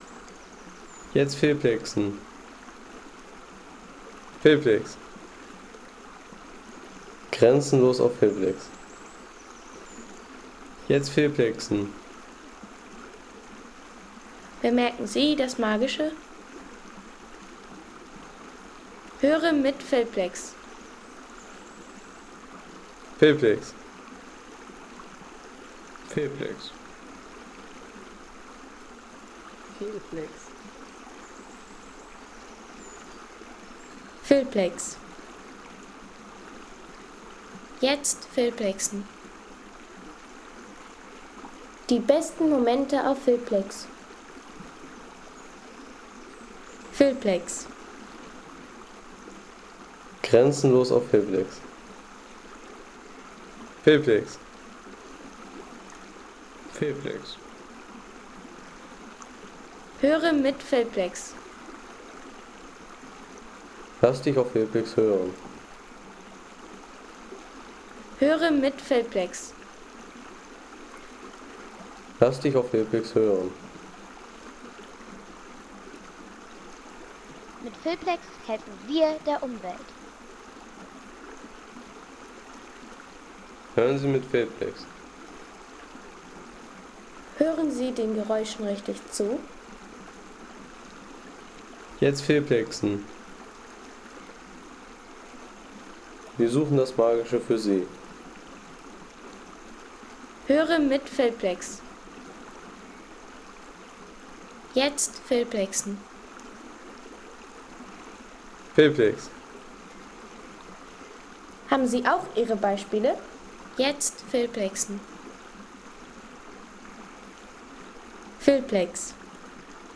Tonaufnahme des fließenden Wassers im Kirnitzschfluss.